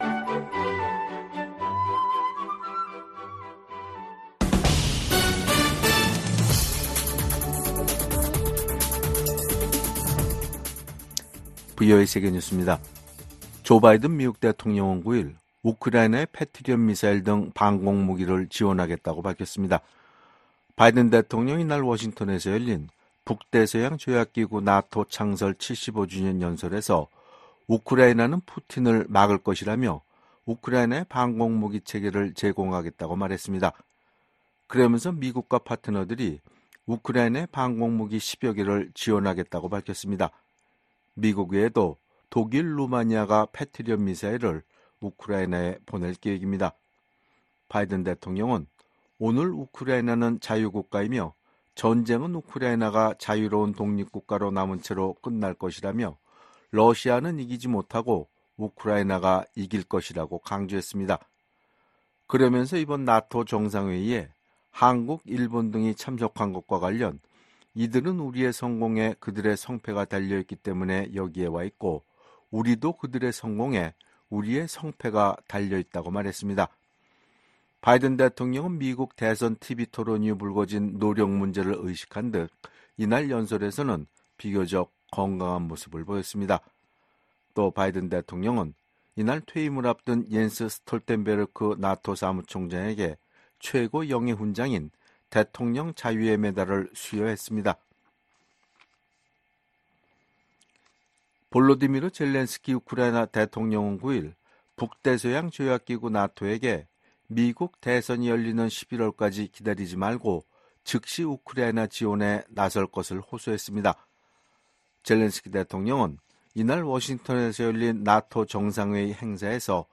세계 뉴스와 함께 미국의 모든 것을 소개하는 '생방송 여기는 워싱턴입니다', 2024년 7월 10일 저녁 방송입니다. '지구촌 오늘'에서는 조 바이든 미국 대통령이 나토 정상회의 연설에서 우크라이나가 블라디미르 푸틴 러시아 대통령을 막을 수 있다고 말한 소식 전해드리고 '아메리카 나우'에서는 조 바이든 대통령의 거취와 관련한 입장을 정리하기 위한 민주당 상하원 연쇄 회동에서 의원들이 바이든 대통령에 대한 우려는 표명한 소식 전해드립니다.